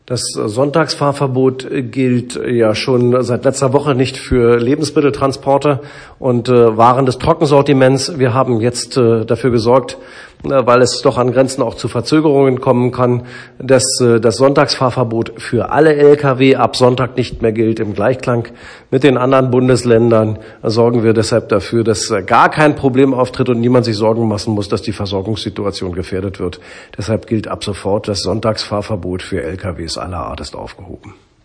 Weiter sagte der Minister
buchholz_aufhebung_lkw_verbot.mp3